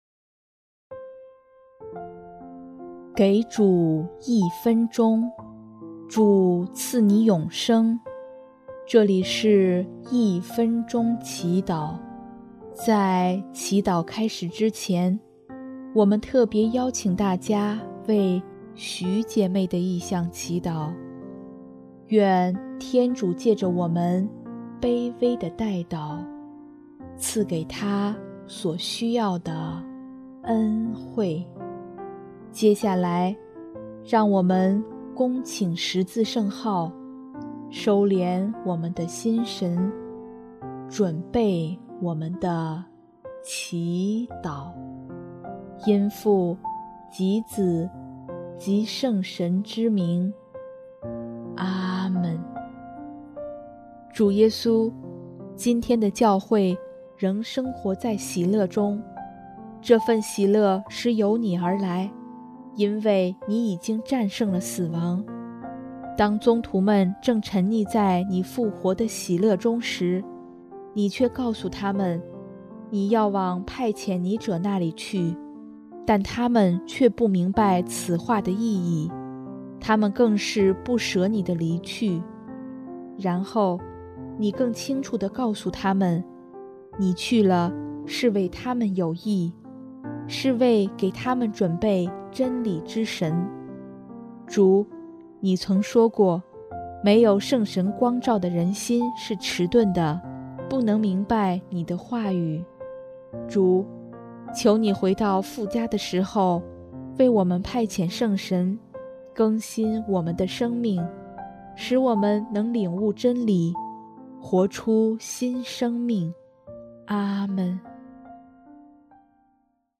音乐：第四届华语圣歌大赛参赛歌曲《圣神之歌》